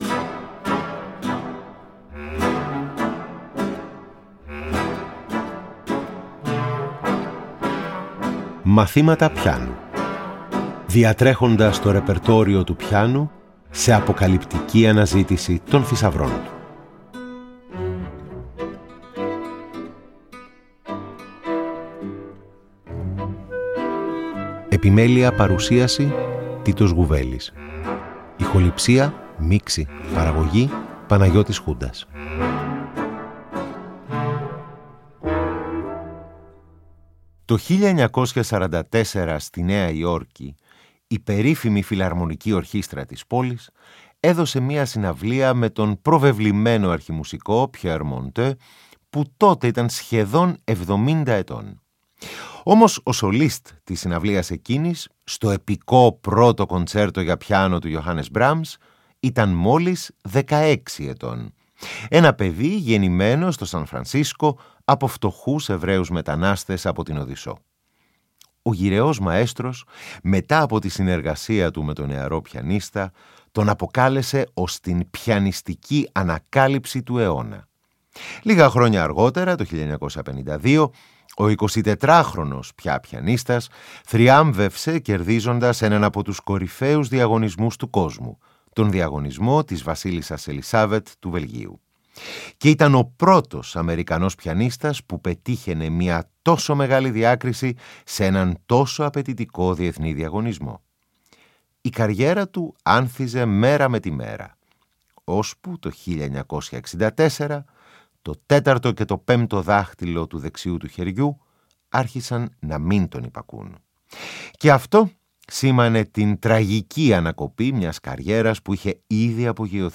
Ακούμε παλιές και πρόσφατες ηχογραφήσεις του, από έργα του Johann Sebastian Bach μέχρι διάσημες Χολιγουντιανές μελωδίες!
Εγχείρημα το οποίο ενισχύει η ενίοτε ζωντανή ερμηνεία χαρακτηριστικών αποσπασμάτων κατά τη διάρκεια της εκπομπής.